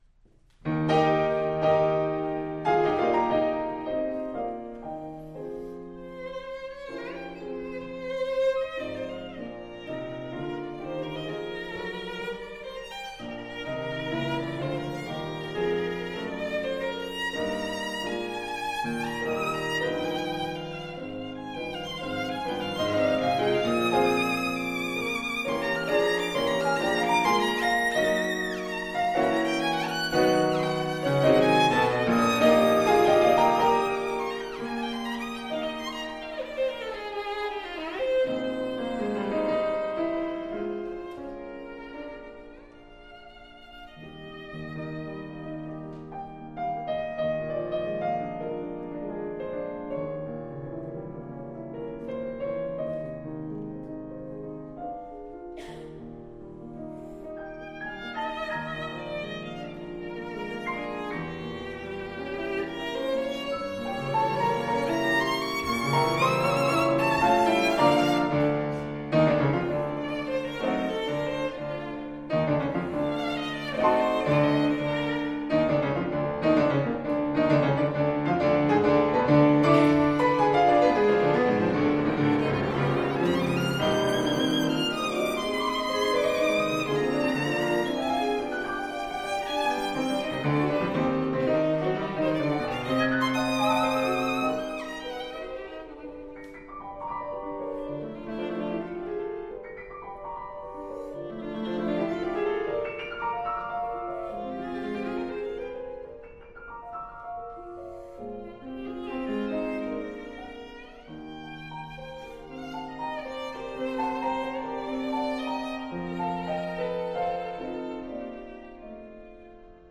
Style: Classical
Audio: Boston - Isabella Stewart Gardner Museum
violin
piano
strauss_violin_sonata_op18.mp3